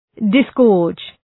{dıs’gɔ:rdʒ}